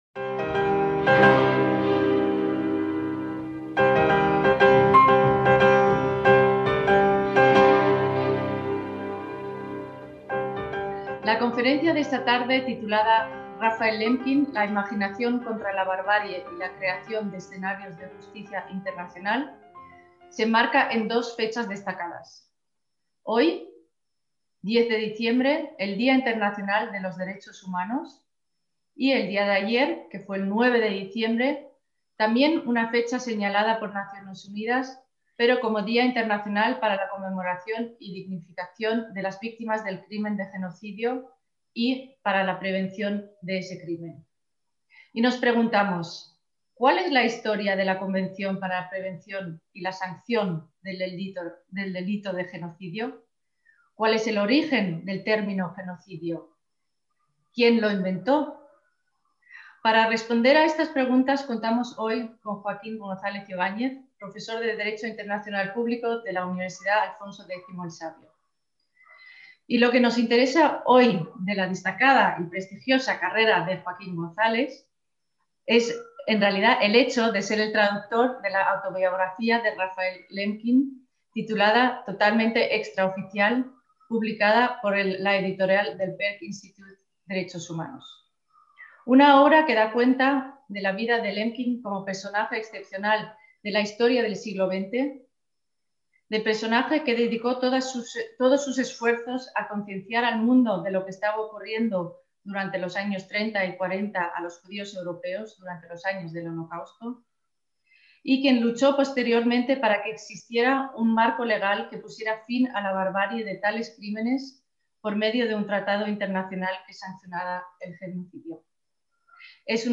ACTOS "EN DIRECTO" - Durante la Segunda Guerra Mundial, en un mensaje radiado de la BBC en 1941, Winston Churchill afirmó que los nazis estaban cometiendo un crimen sin nombre.